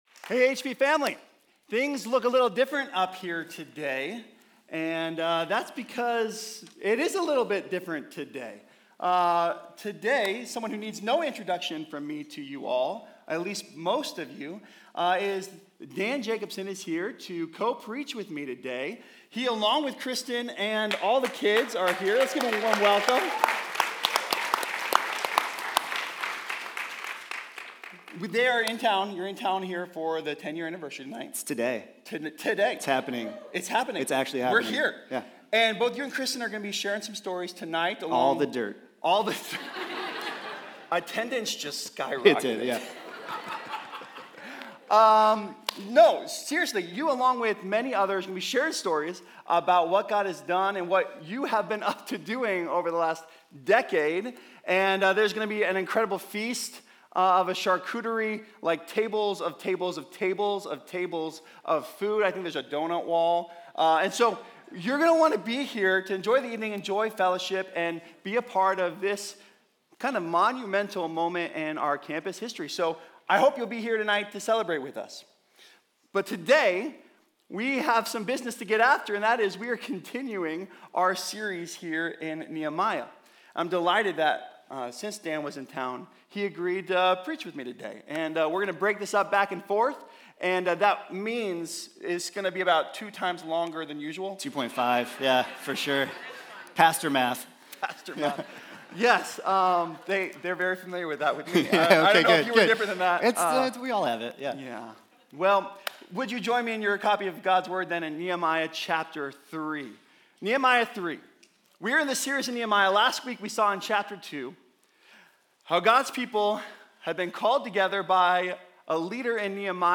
Nehemiah 3 | How God's People Get Things Done | Nehemiah - HP Campus Sermons